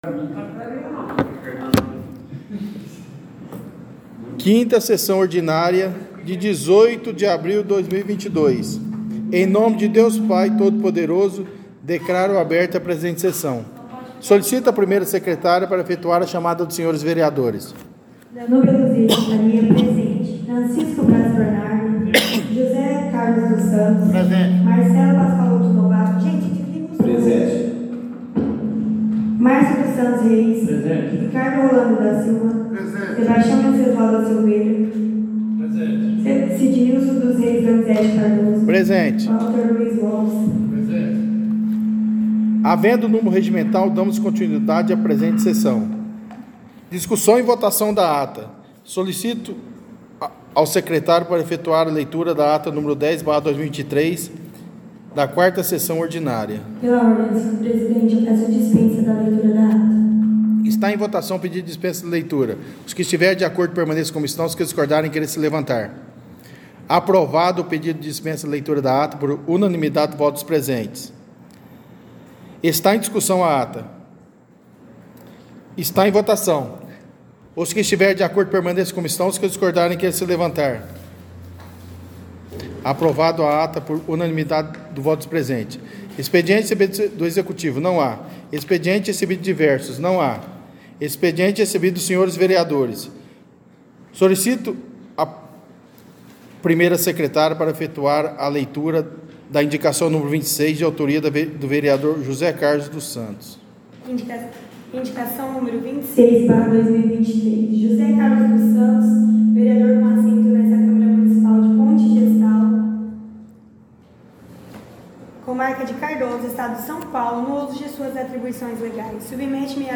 Áudio 6ª Sessão Ordinária – 18/04/2023